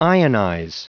Prononciation du mot ionize en anglais (fichier audio)
Prononciation du mot : ionize